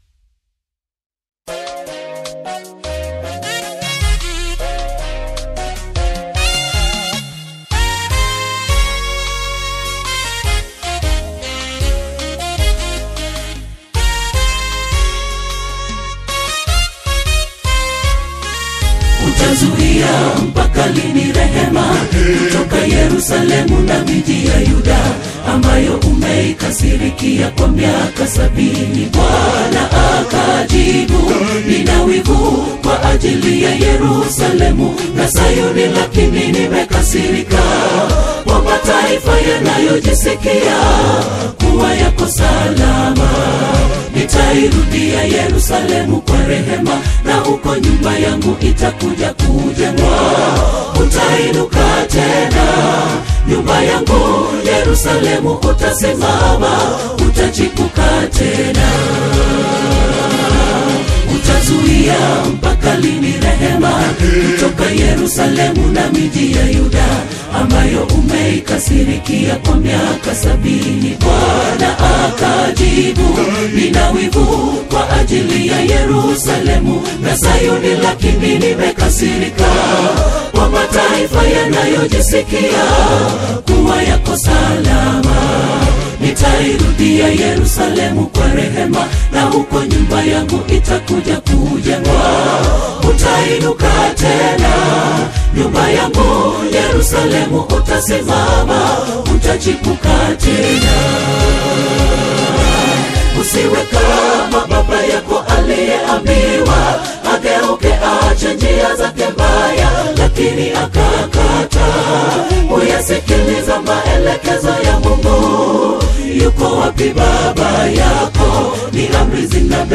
a poignant and sobering new single
is a premier Kenyan ensemble